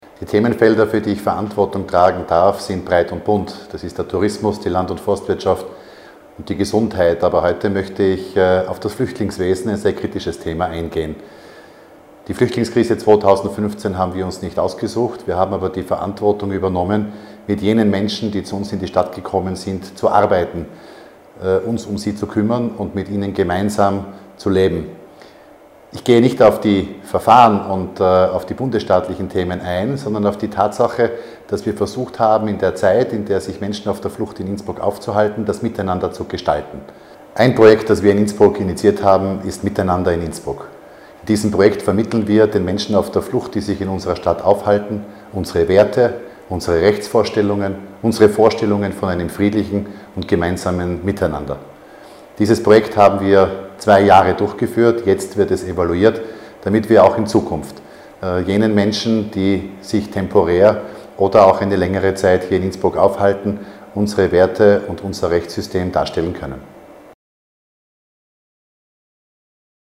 OT von Stadtrat Franz X. Gruber